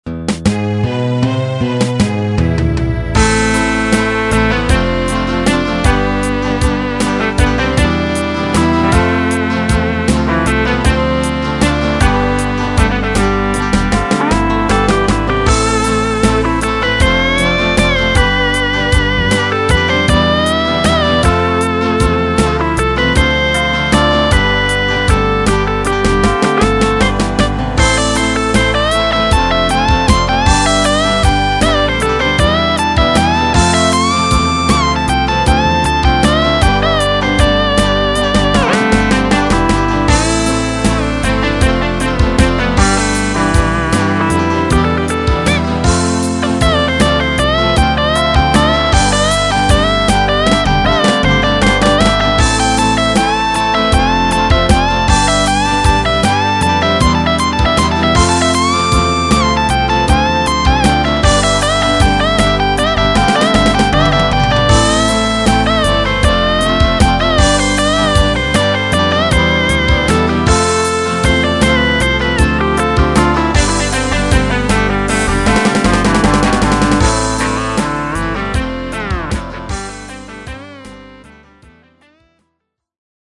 Guitare Electrique
MIDI